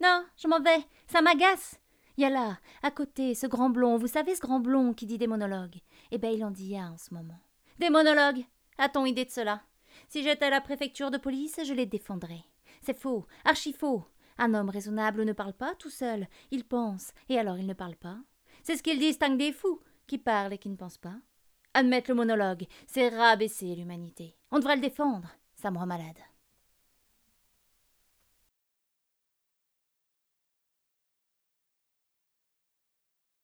Voix off féminine francophone
Théâtre Feydeau
Middle Aged